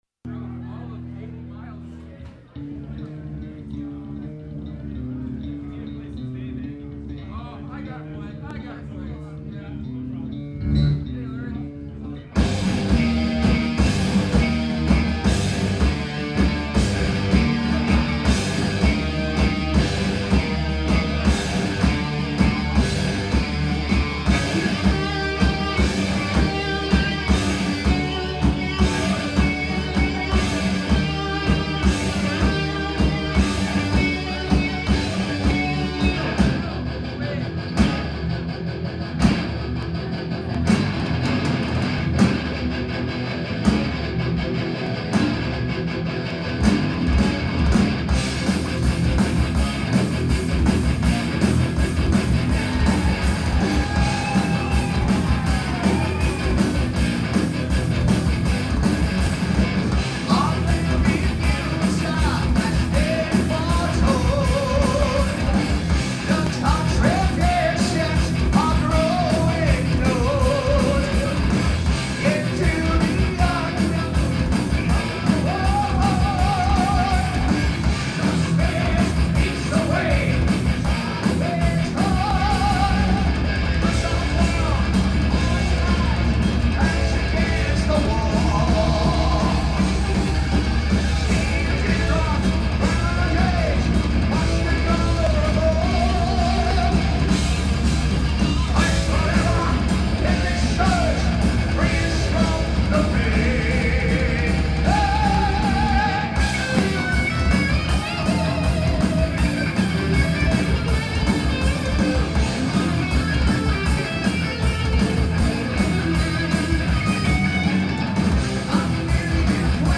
live crowd recording
guitar
drums
bass